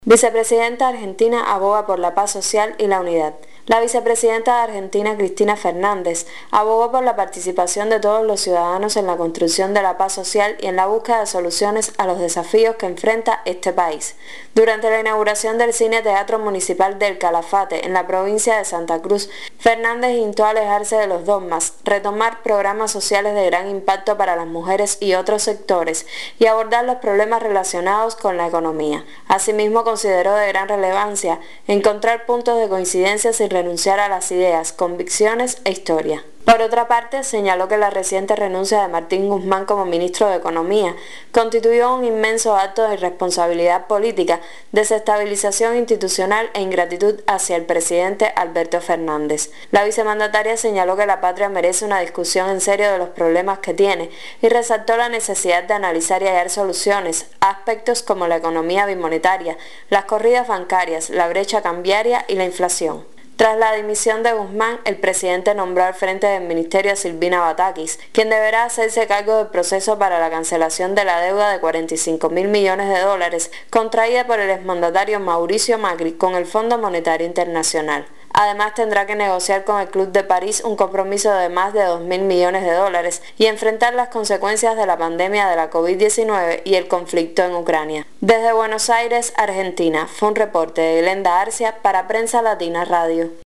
desde Buenos Aires